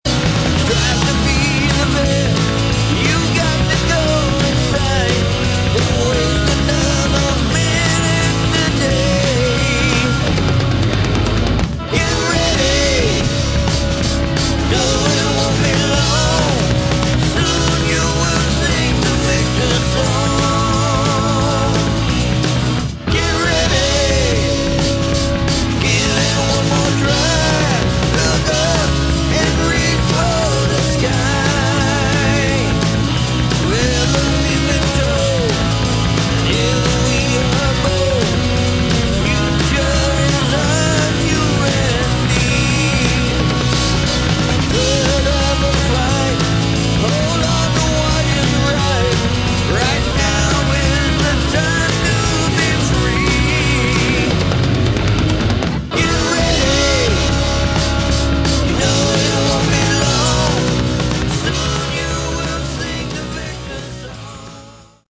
Category: Melodic Hard Rock
guitar, vocals, organ
guitar, bass, vocals
drums